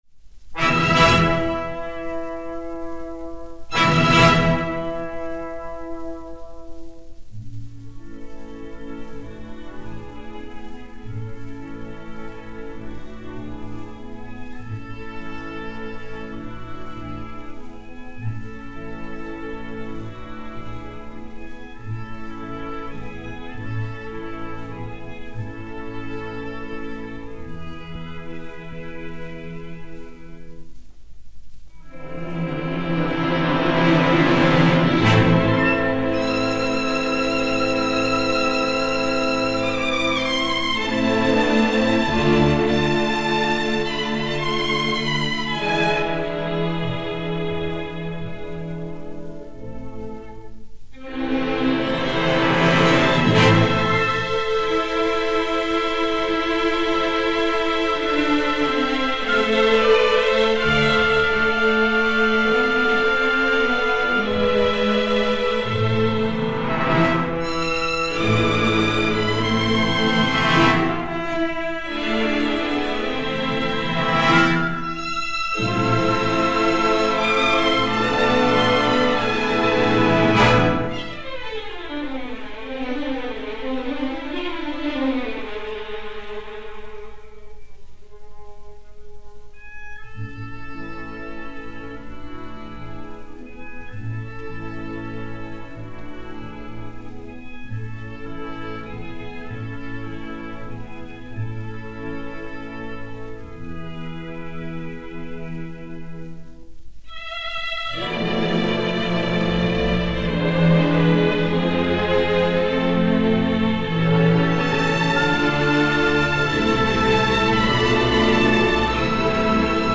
Orchestre de l'Association des Concerts Colonne dir Louis Fourestier
CPT 1300-1 et CPT 1301-1, enr. au Théâtre des Champs-Elysées le 18 novembre 1949